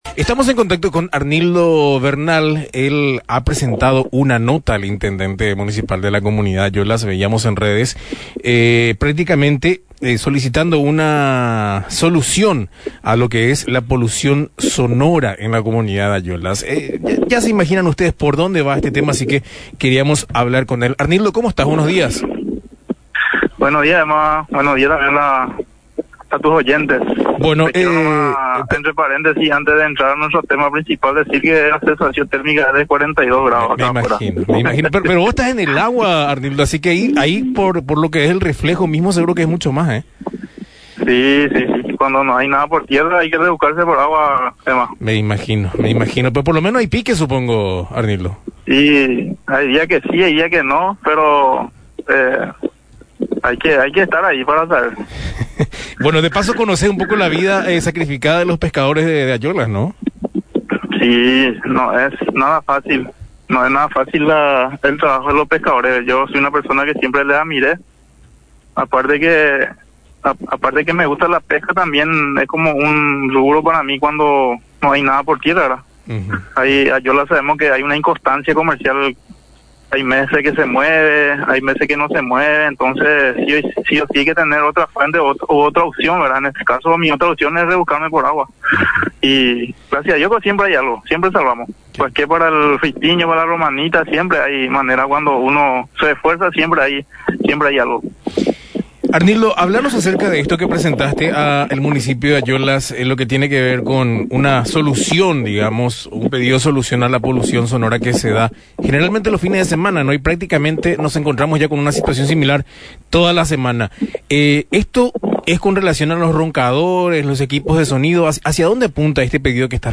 En diálogo con Misión FM